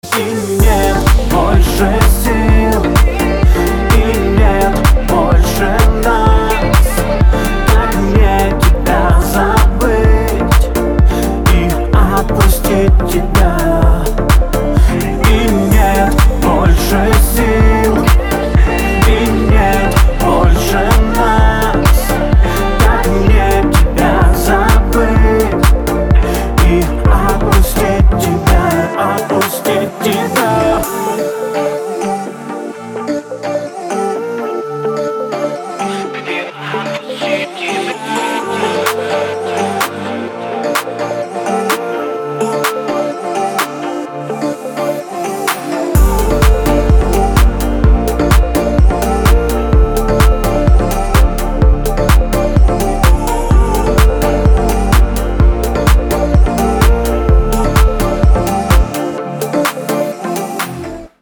• Качество: 256, Stereo
поп
мужской голос
грустные
dance